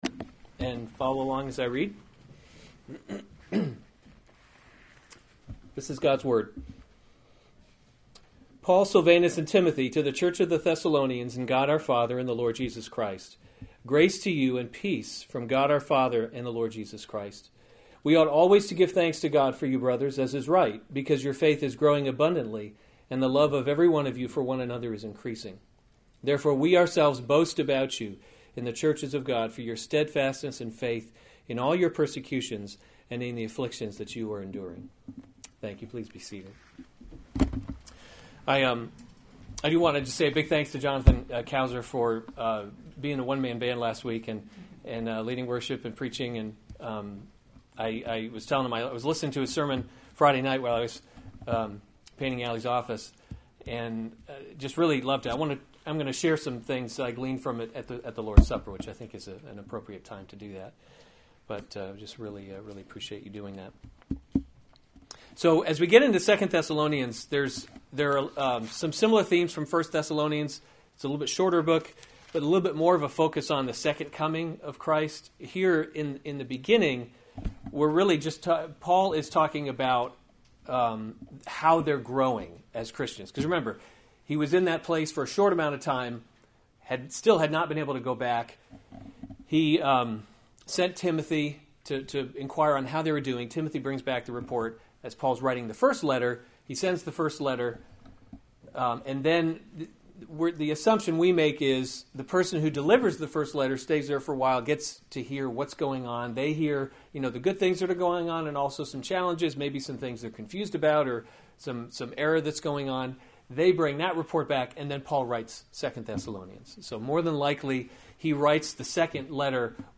May 7, 2016 2 Thessalonians – The Christian Hope series Weekly Sunday Service Save/Download this sermon 2 Thessalonians 1:1-4 Other sermons from 2 Thessalonians Greeting 1:1 Paul, Silvanus, and Timothy, To […]